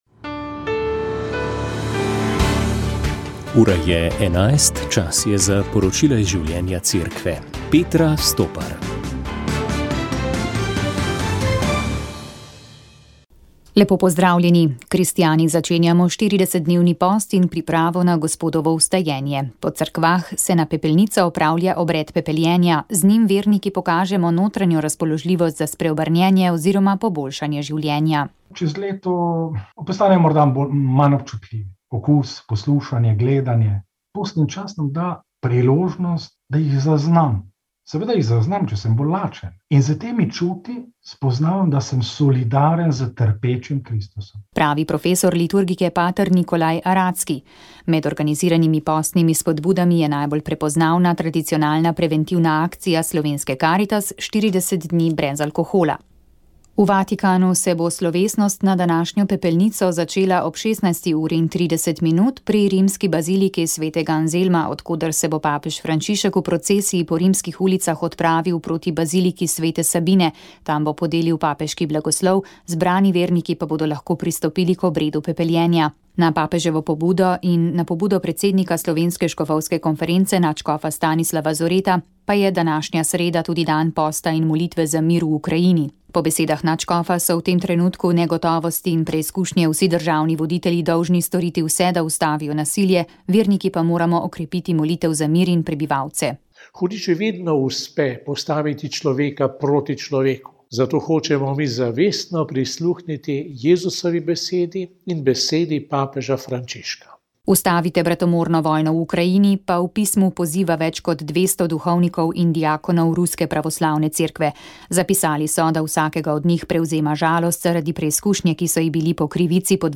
V prvem postnem večeru se je zabavna medgeneracijska oddaja Moja generacija umaknila bolj resni temi. Imeli smo pravi moški pogovor. Ne samo zaradi petih moških sogovornikov, ampak tudi zaradi teme: spoznavali smo moško duhovnost. Sredi aprila bo namreč na Sladki Gori že četrto vseslovensko srečanje mož, očetov in sinov.